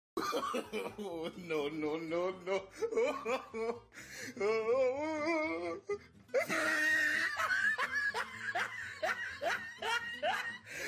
oh-no-no-no-no-laugh_16Ht4IQ.mp3